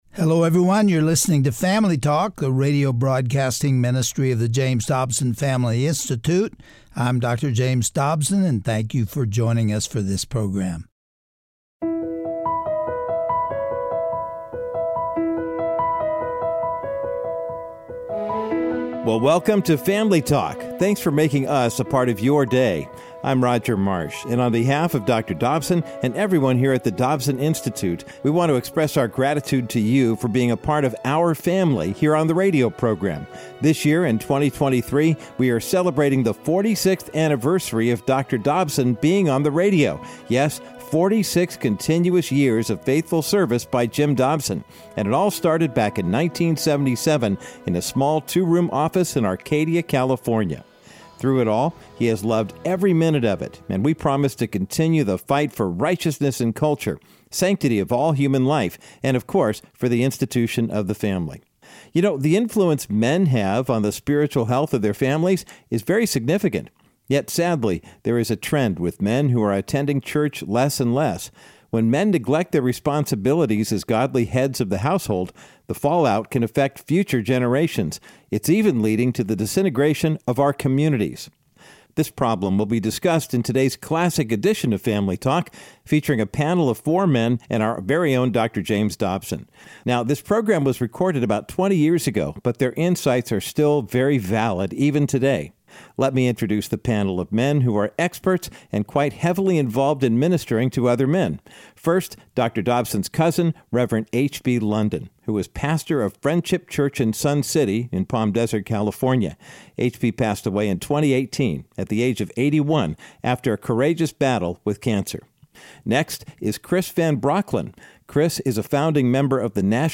As a result, more and more are ignoring their duties as spiritual heads of their households. On today’s classic edition of Family Talk, Dr. James Dobson discusses this issue with a panel of experts who serve in men’s ministry.